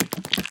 mob / spider / step2.ogg
step2.ogg